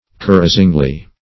carousingly - definition of carousingly - synonyms, pronunciation, spelling from Free Dictionary Search Result for " carousingly" : The Collaborative International Dictionary of English v.0.48: Carousingly \Ca*rous"ing*ly\, adv.
carousingly.mp3